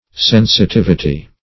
Sensitivity \Sen`si*tiv"i*ty\, n.